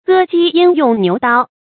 割雞焉用牛刀 注音： ㄍㄜ ㄐㄧ ㄧㄢ ㄩㄥˋ ㄋㄧㄨˊ ㄉㄠ 讀音讀法： 意思解釋： 殺只雞何必用宰牛的刀。